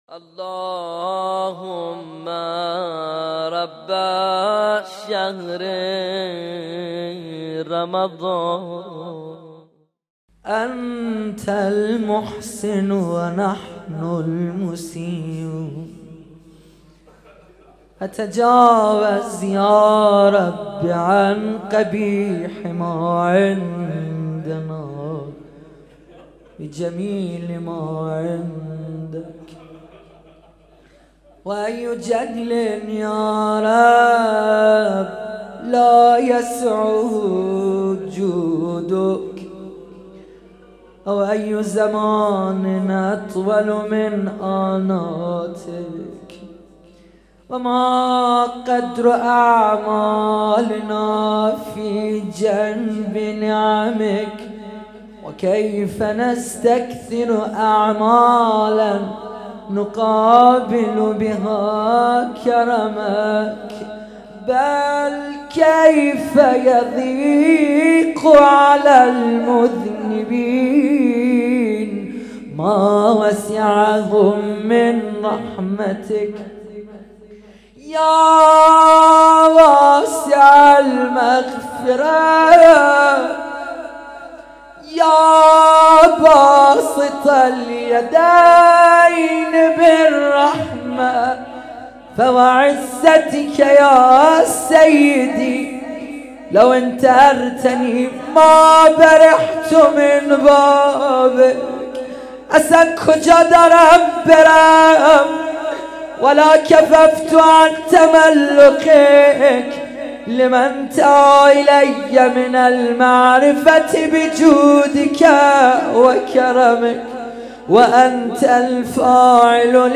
قرائت دعای أبو حمزه الثمالی (بخش سوم)